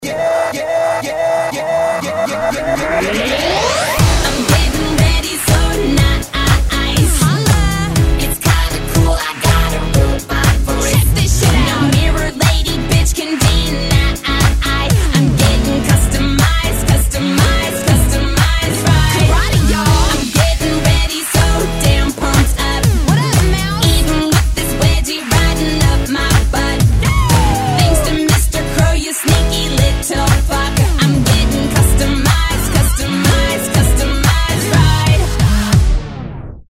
из Клубные